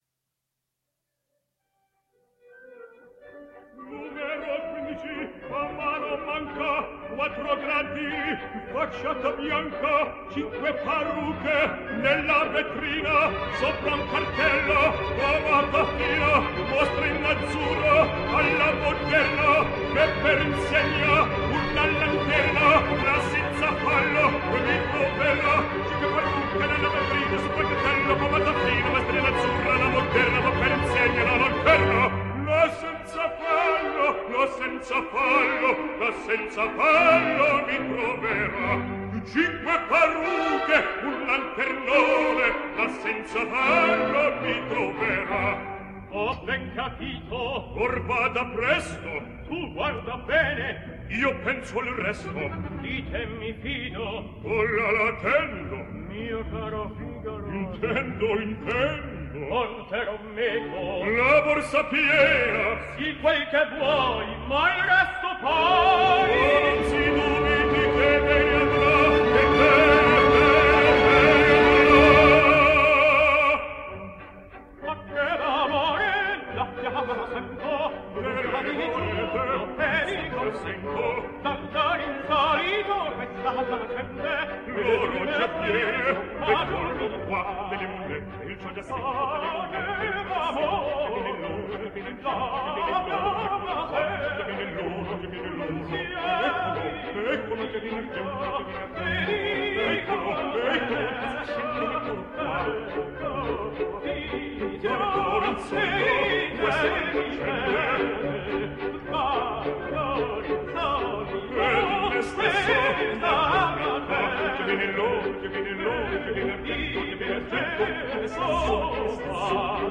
Italian Tenor.